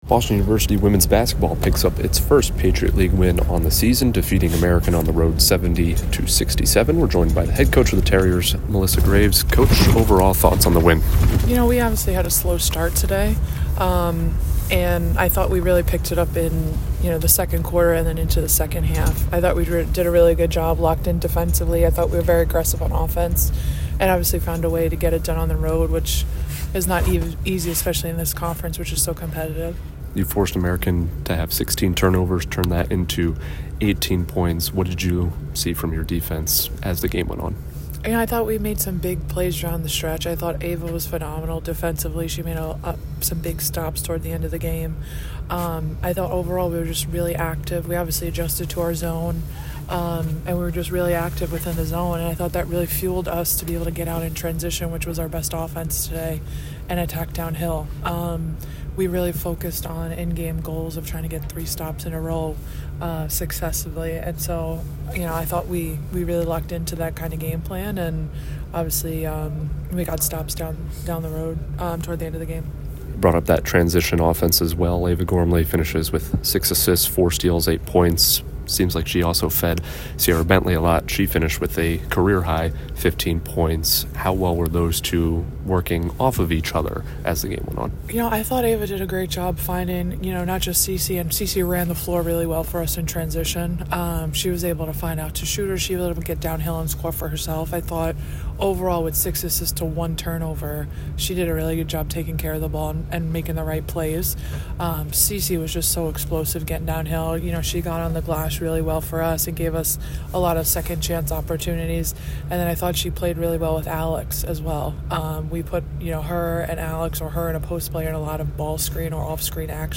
WBB_American_1_Postgame.mp3